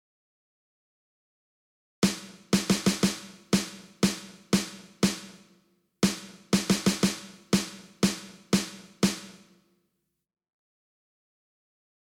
スネアドラムでリズムを打つとこんな感じになります。